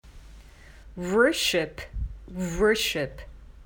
worshipwˈɚːʃɪp
⇒ 発音見本は
worは、強あいまい母音なのであごはほぼ閉じています。
worship.mp3